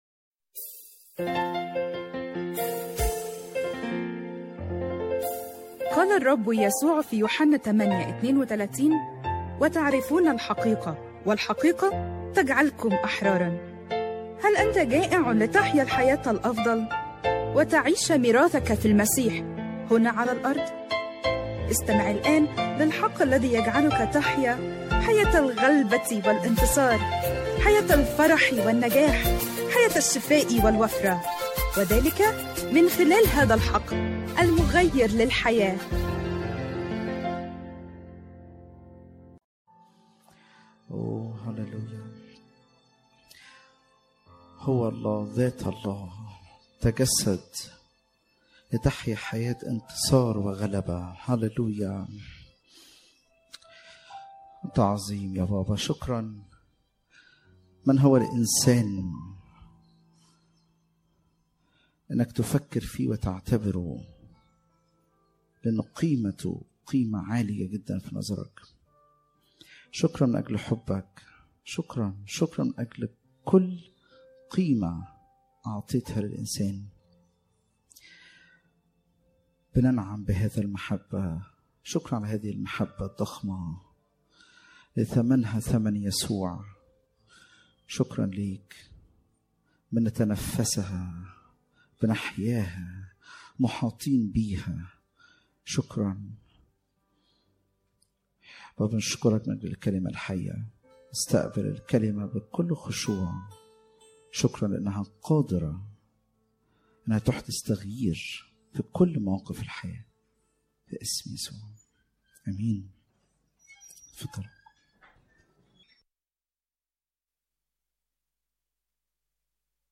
سلسلة القي علي الرب همك 7 أجزاء العظة على ساوند كلاود اضغط هنا من تأليف وإعداد وجمع خدمة الحق المغير للحياة وجميع الحقوق محفوظة.